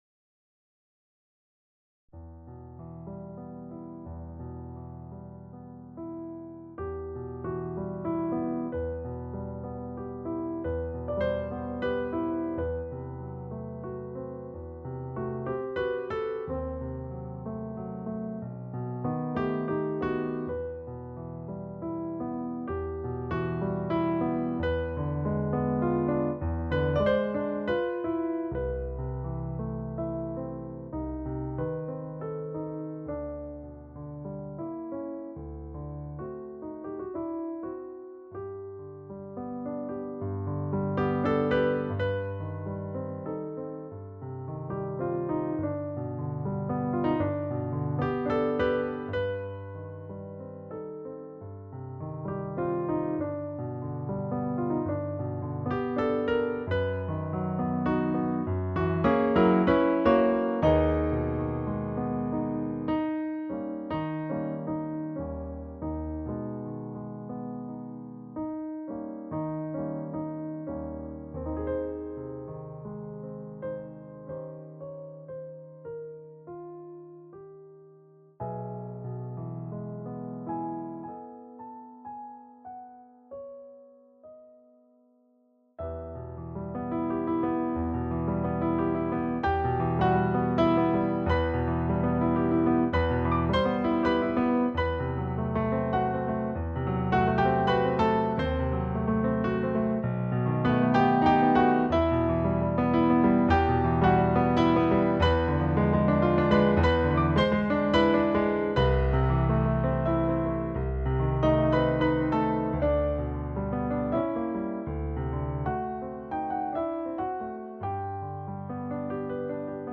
Romance in E Minor.mp3